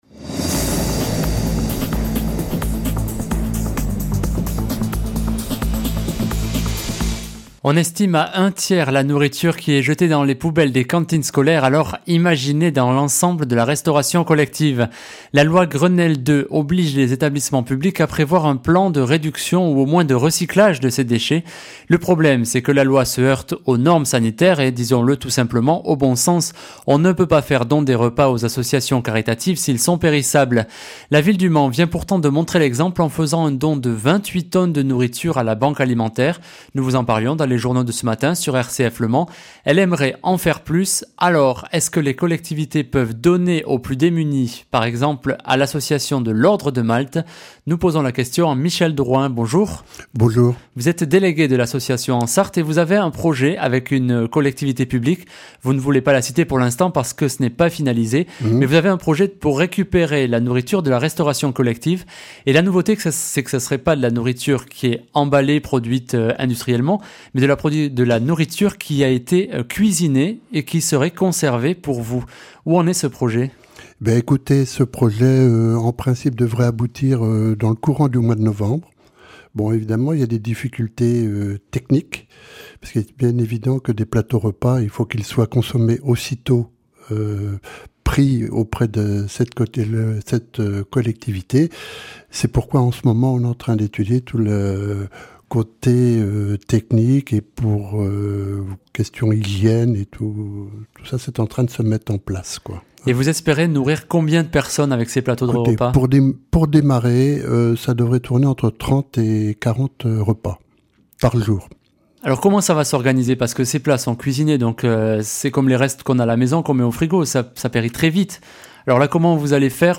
Les podcasts Interview - Page 87 sur 174 - La FRAP